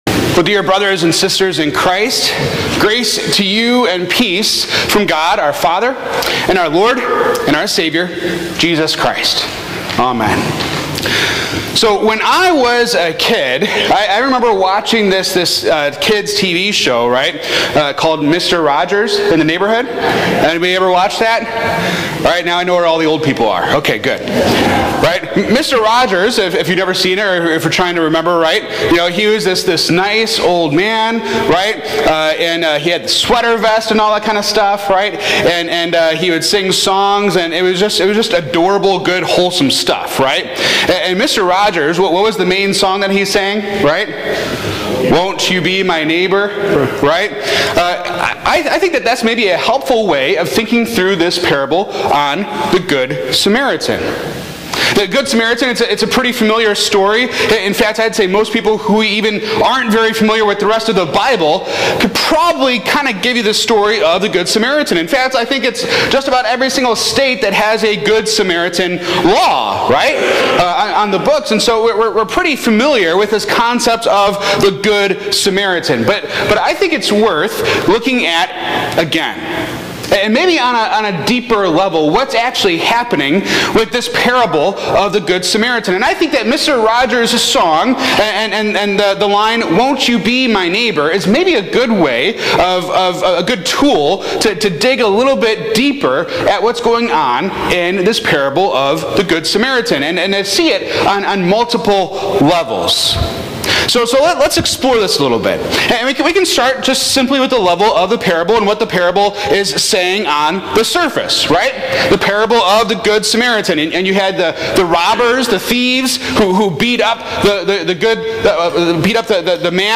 This sermon explores the Parable of the Good Samaritan, starting with Mr. Rogers' iconic question.